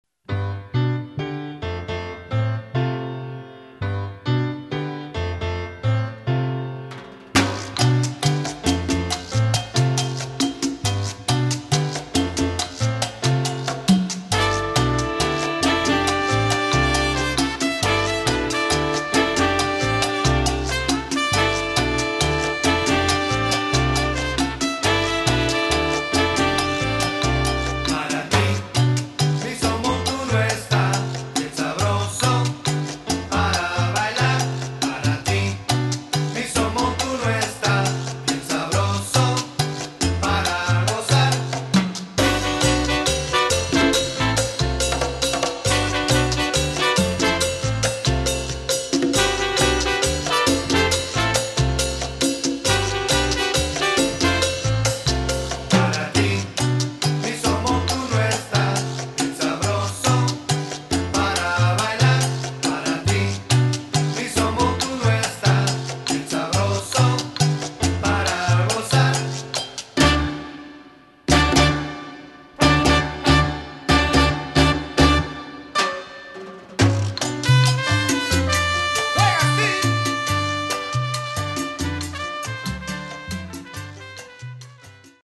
Category: salsa
Style: cha cha
Solos: vocal – easy vocal part with no pregón (ad lib)